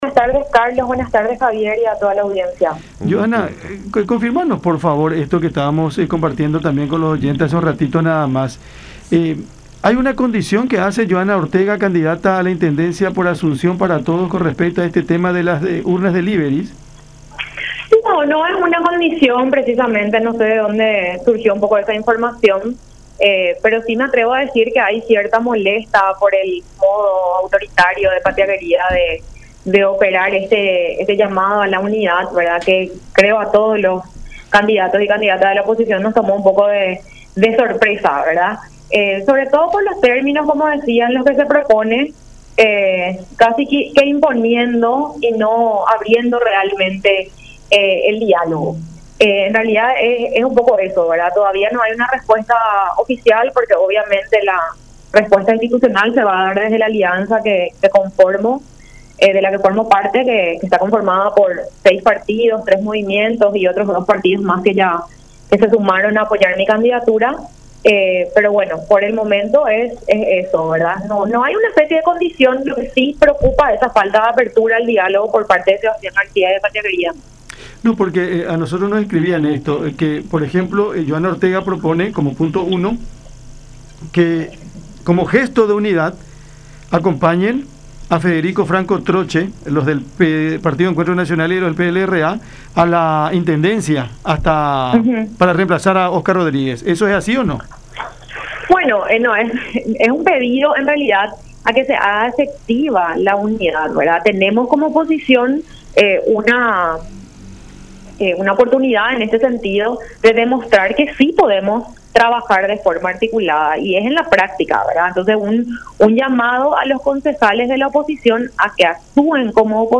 en conversación con Cada Siesta por La Unión.